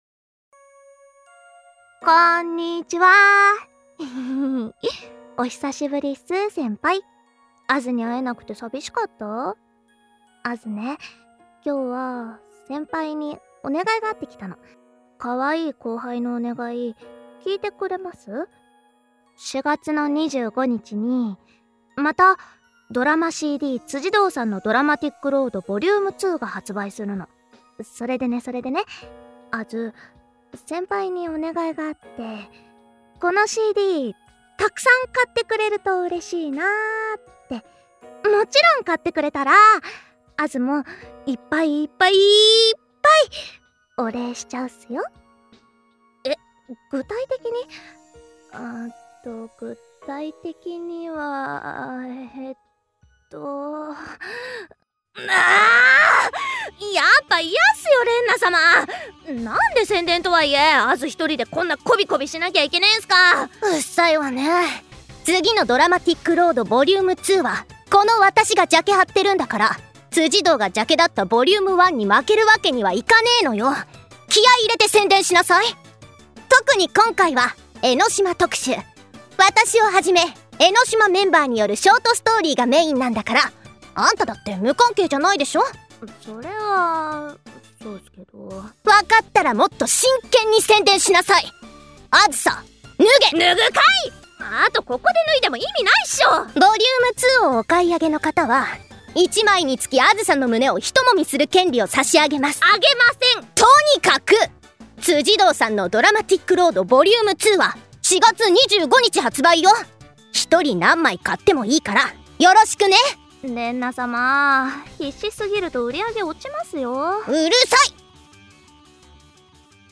ドラマＣＤ「辻堂さんのドラマティックロード」Vol.2 紹介ページです